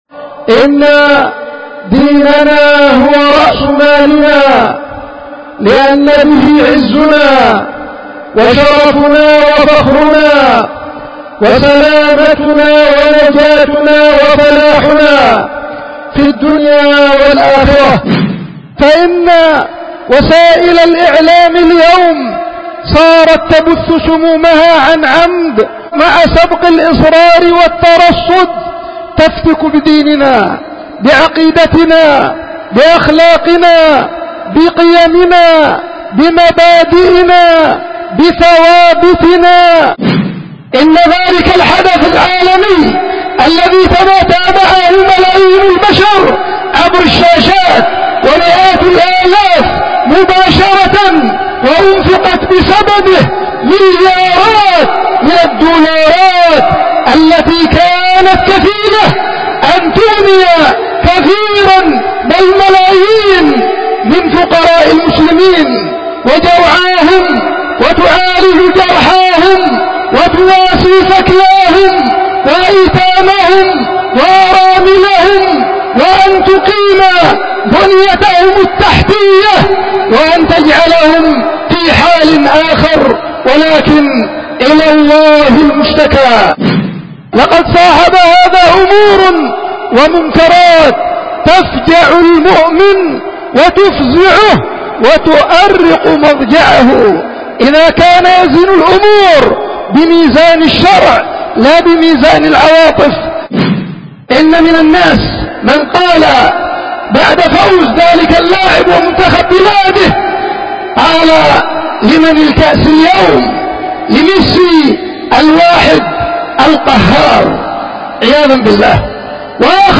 خطبة الجمعة
القيت في دار الحديث في مدينة دار السلام العلمية بيختل المخا